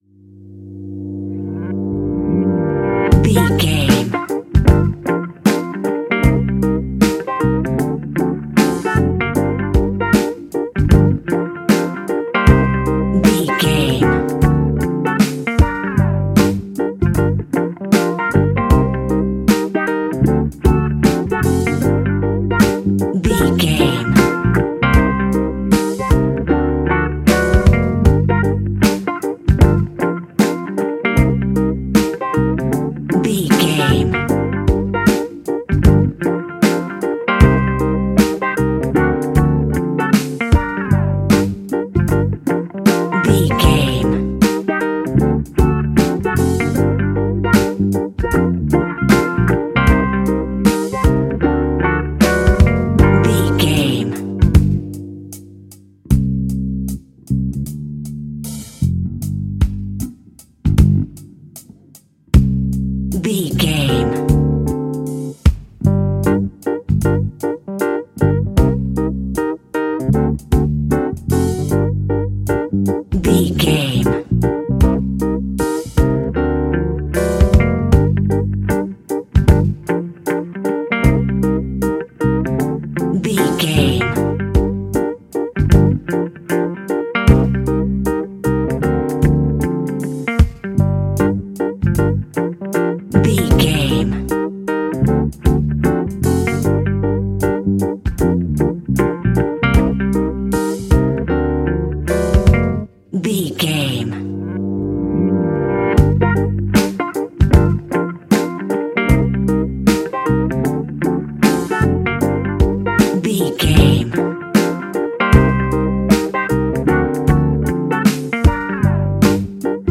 Epic / Action
Fast paced
In-crescendo
Uplifting
Ionian/Major
instrumentals